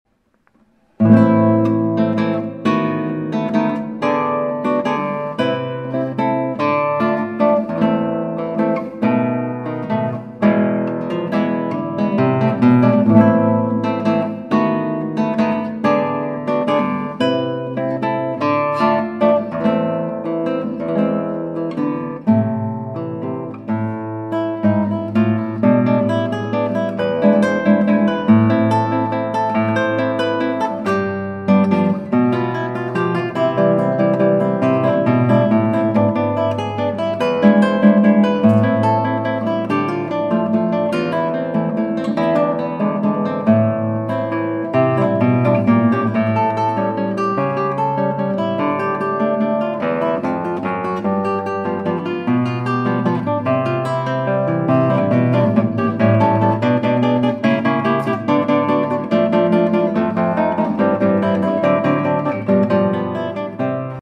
Musik für Gitarre